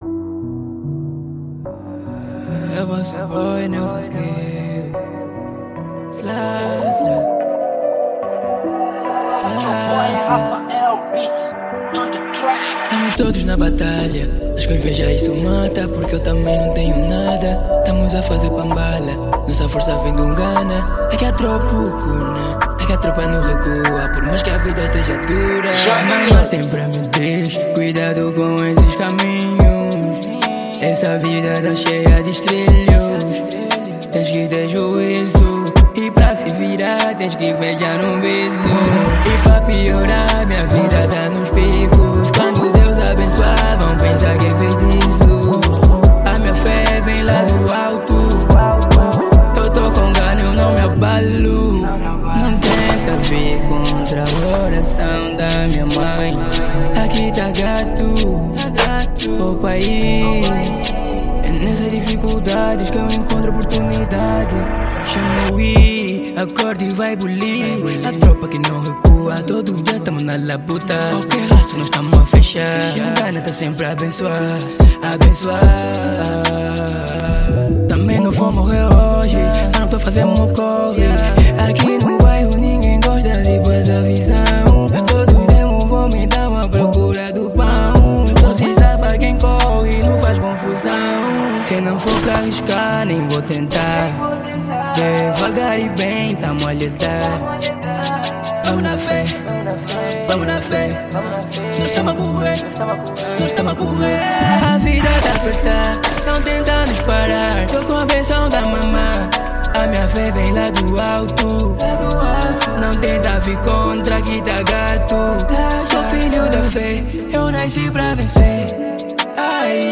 Gênero: Drill